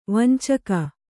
♪ vancaka